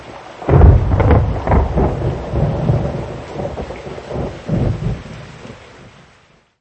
prehistorie_Onweer.mp3